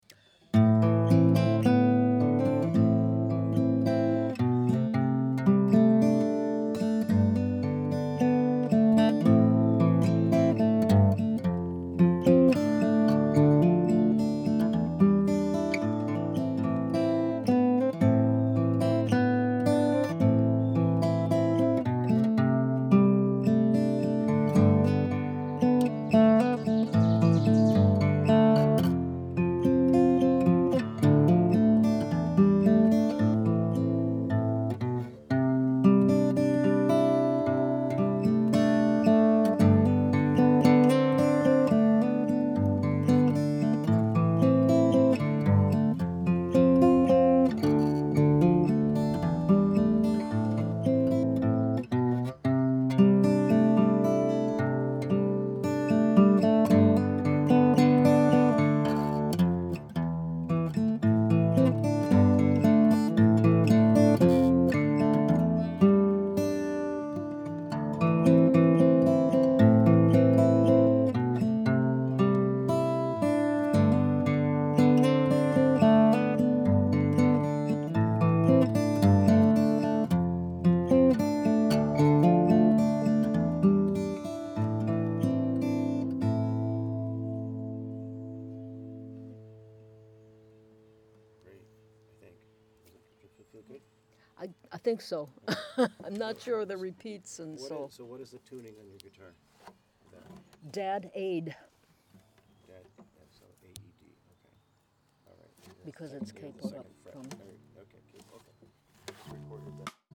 Key: A minor
Form: Air
Guitar
(DADBAE, capo 2nd fret):
Henry-B-solo-guitar-MP3.mp3